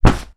Punching Bag Powerful A.wav